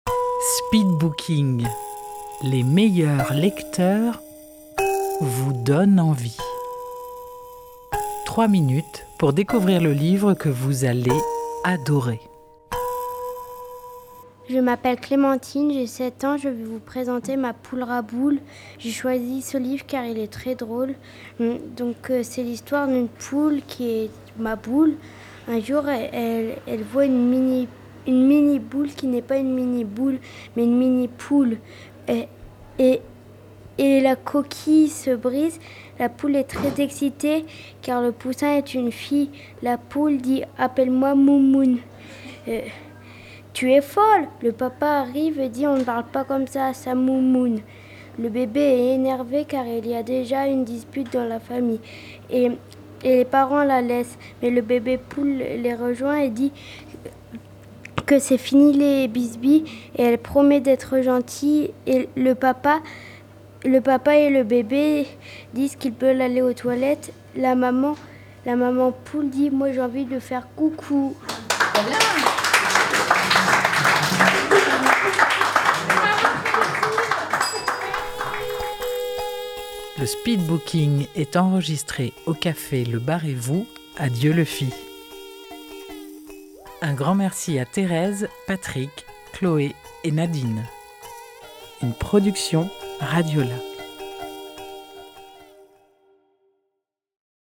Enregistré en public au Bar & Vous à Dieulefit.